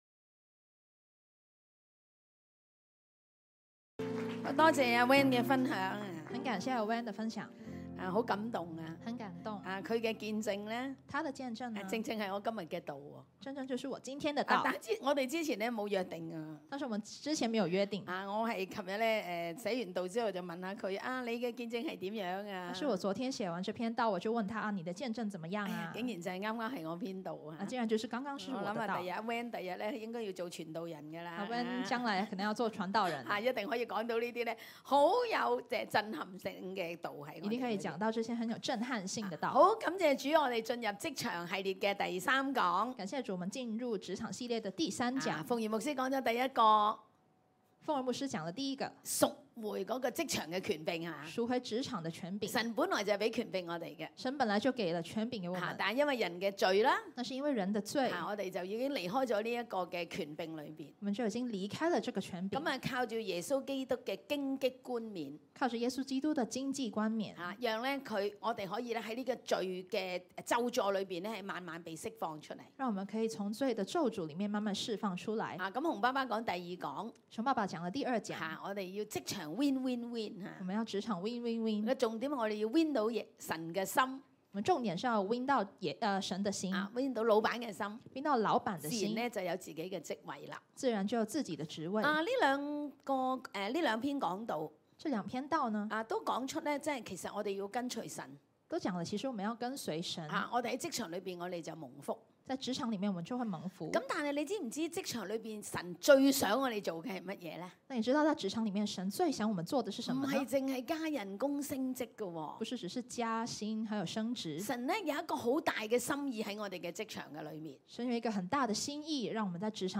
下載 講道筆記 2021年10月16-17日 生命樹系列(28) 職場篇三： 職場健康碼